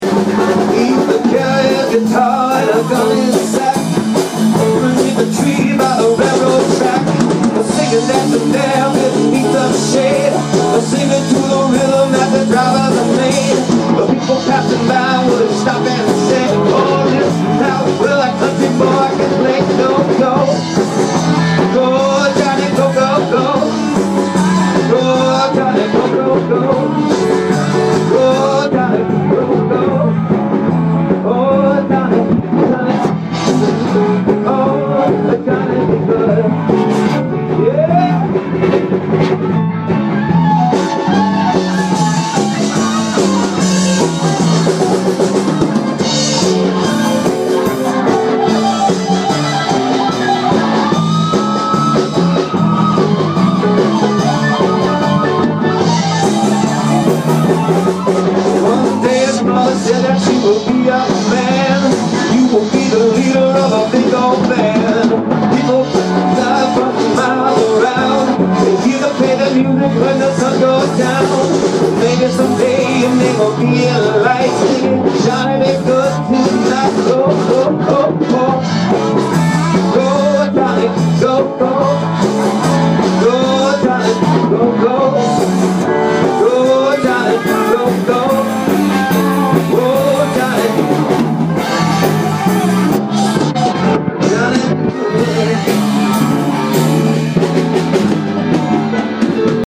A high-energy rendition